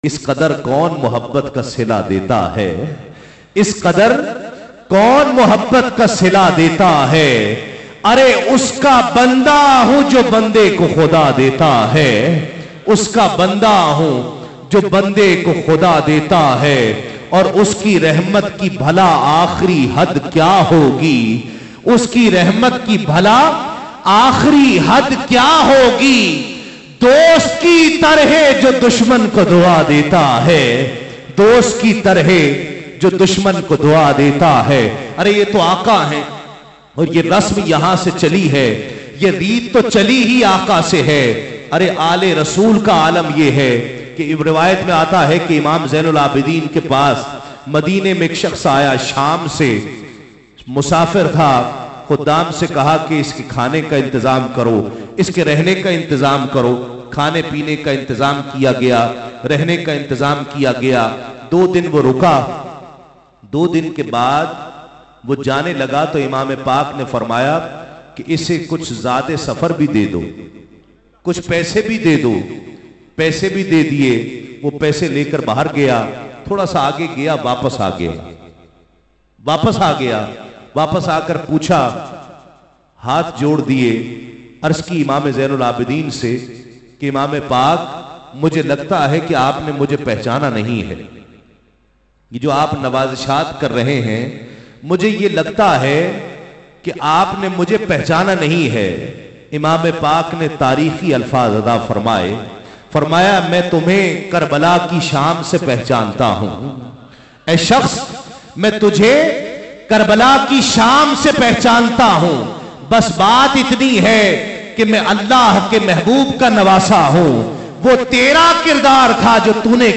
URDU NAAT